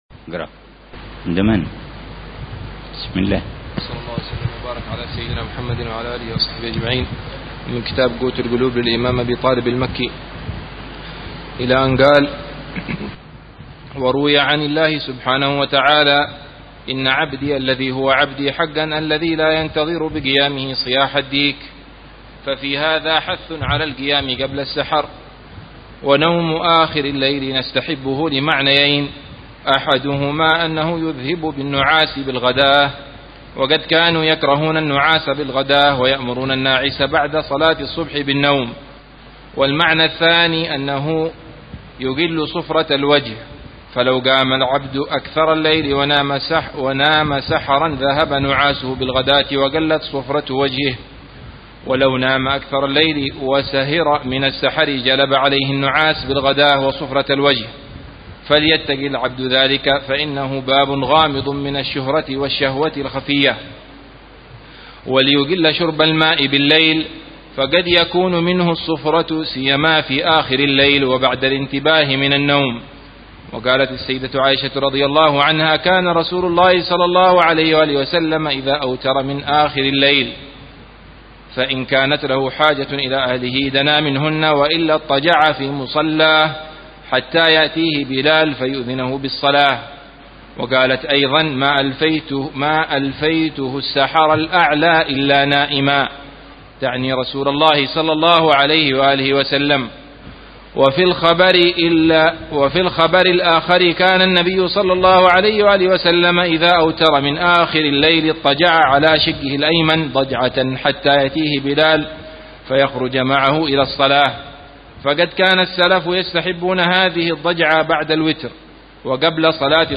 قراءة بتأمل وشرح لمعاني كتاب قوت القلوب للشيخ: أبي طالب المكي ضمن دروس الدورة التعليمية الخامسة عشرة بدار المصطفى 1430هجرية.